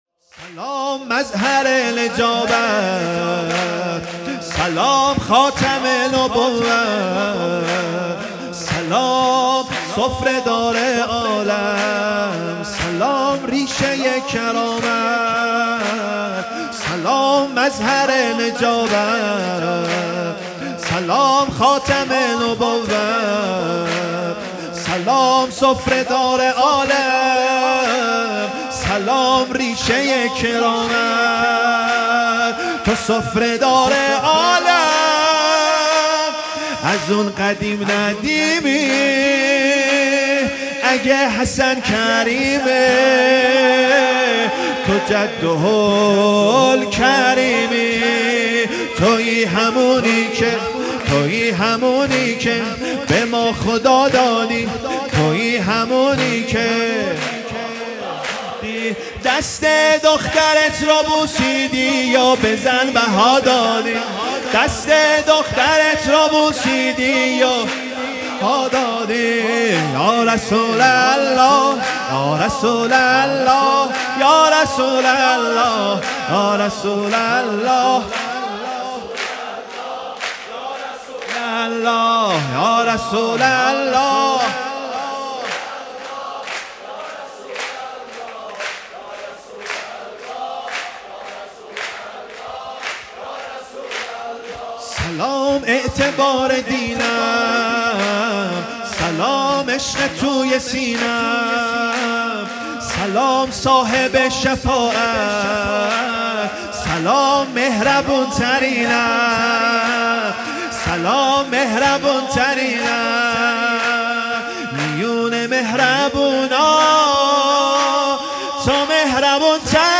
ولادت پیامبر اکرم (ص) و امام جعفر صادق(ع) 1402
تک سرود